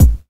• 00's Treble Rich Rap Bass Drum A# Key 09.wav
Royality free bass drum sound tuned to the A# note. Loudest frequency: 641Hz
00s-treble-rich-rap-bass-drum-a-sharp-key-09-0GW.wav